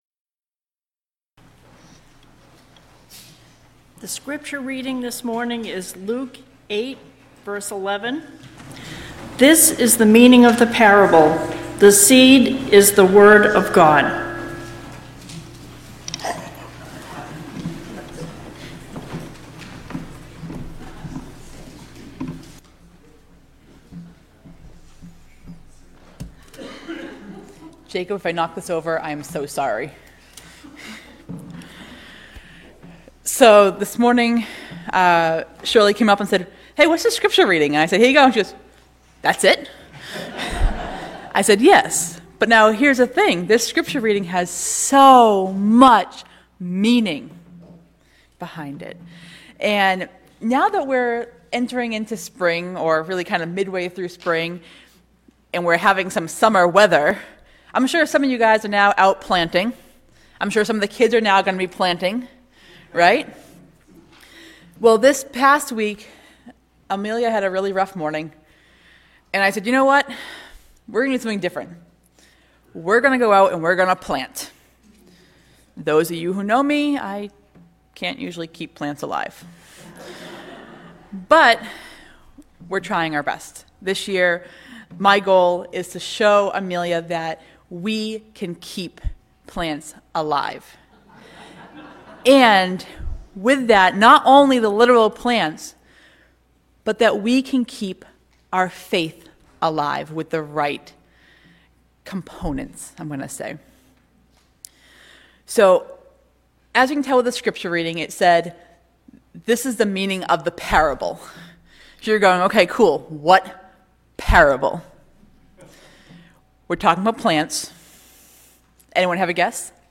Children’s Sunday